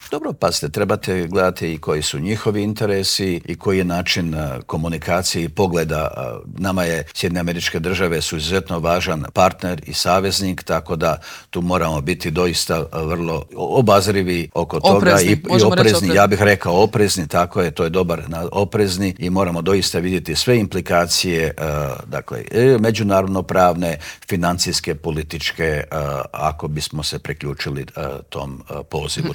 ZAGREB - U Intervjuu tjedna Media servisa gostovao je ministar vanjskih i europskih poslova Gordan Grlić Radman s kojim smo proanalizirali poruke poslane iz Davosa i Bruxellesa, kako od američkog predsjednika Donalda Trumpa i ukrajinskog predsjednika Volodimira Zelenskog tako i od premijera Andreja Plenkovića.